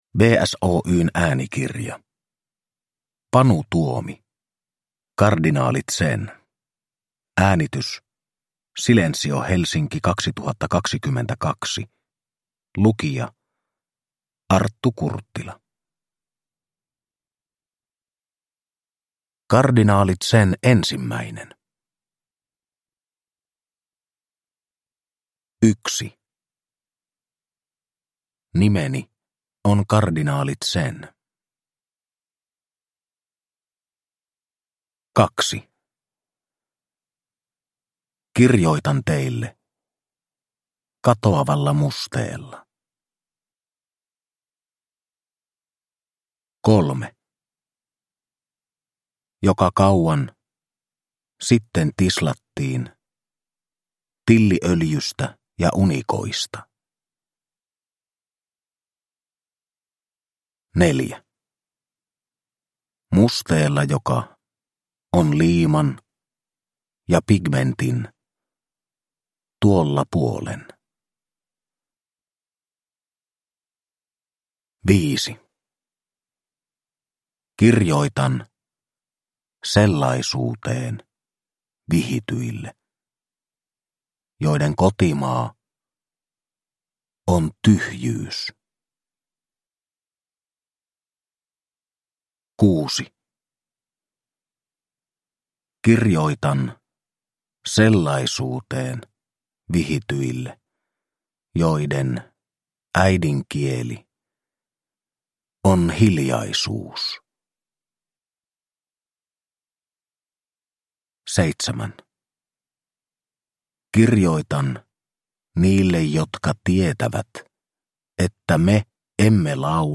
Kardinaali Zen – Ljudbok – Laddas ner
Produkttyp: Digitala böcker